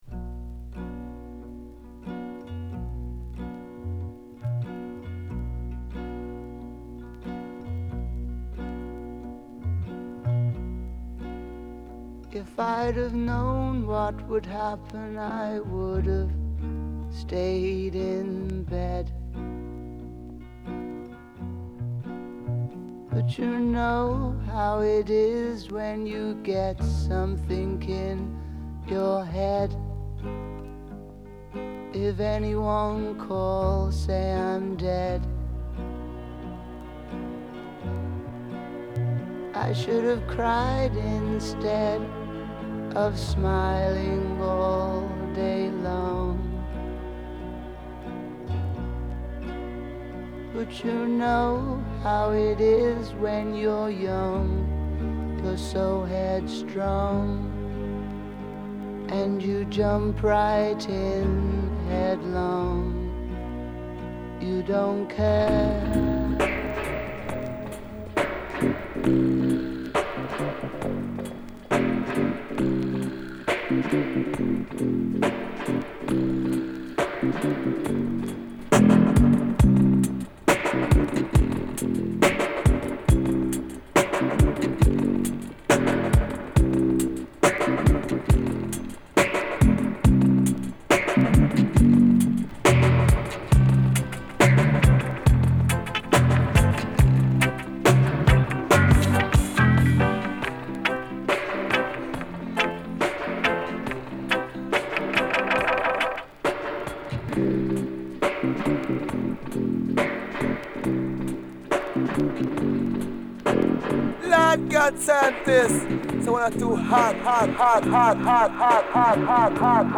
brand new mix
40 minutes of classics stitched together